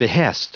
Prononciation du mot behest en anglais (fichier audio)
Prononciation du mot : behest